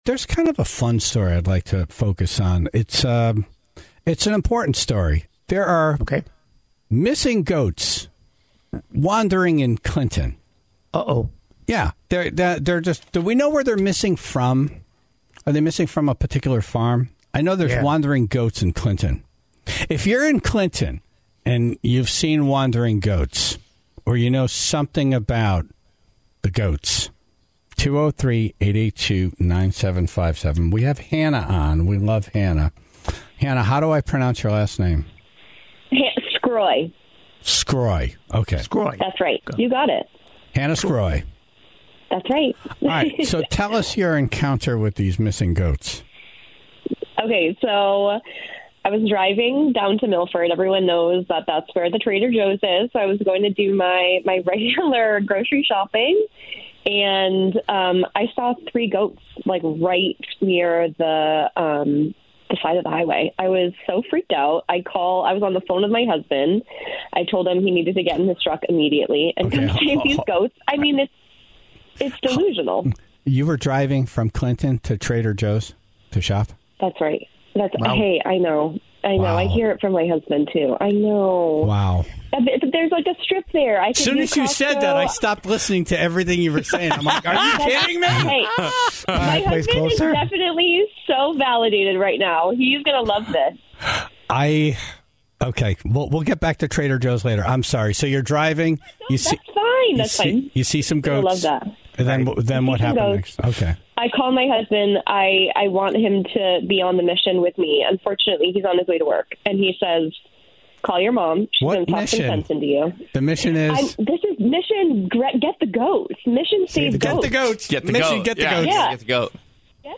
was on the phone to talk about her encounter with the goats, after spending over an hour trying to coax them into her car.